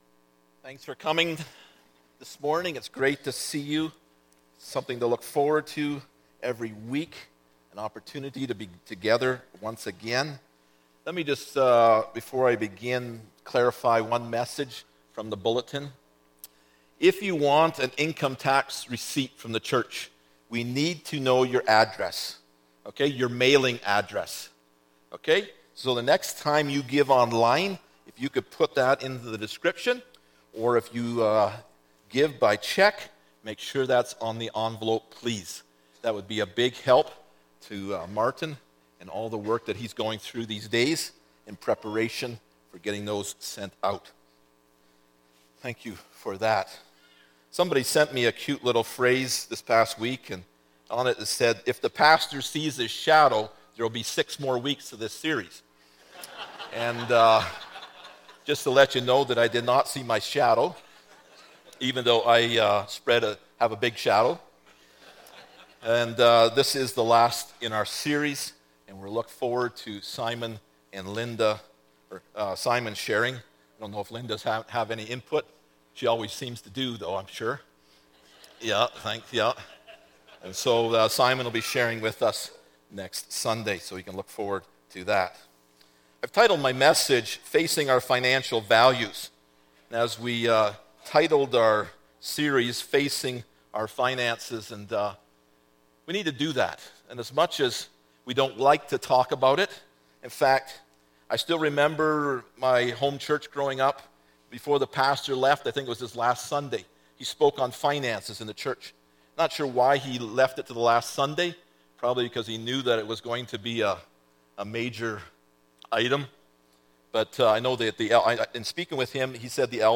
1 Cor 9:25 Service Type: Sunday Morning Topics